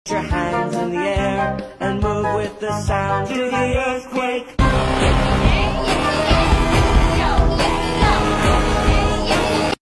egg scream [design and AU sound effects free download